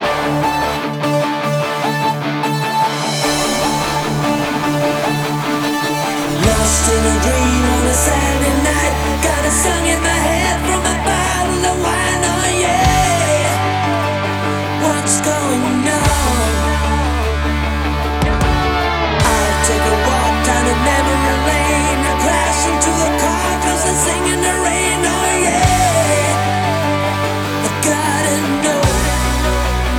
Vocals / Keyboards / Guitars
Bass
Drums